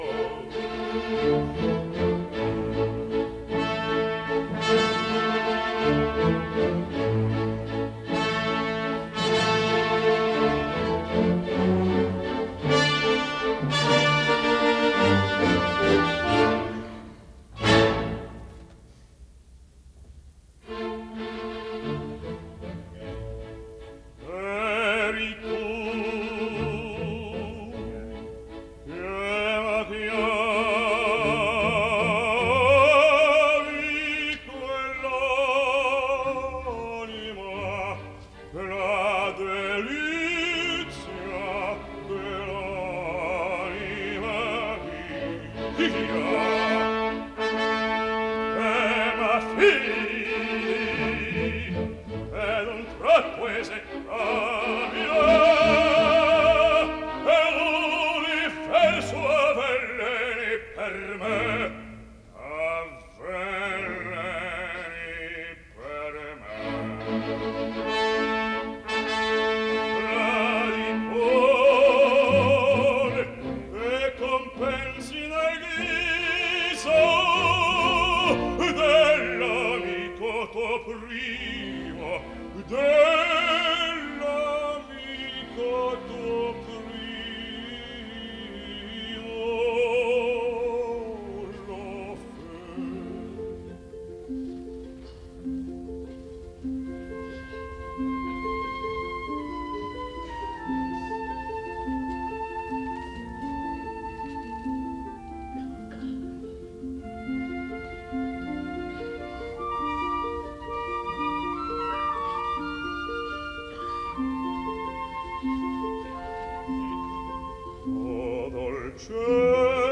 Запись 10 декабря 1955 года, Metropolitan Opera.